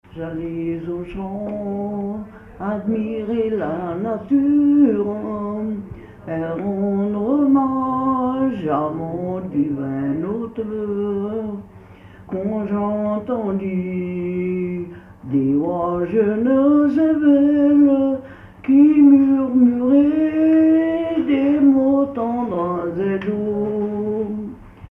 Localisation Mieussy
Pièce musicale inédite